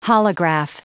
Prononciation du mot holograph en anglais (fichier audio)
Prononciation du mot : holograph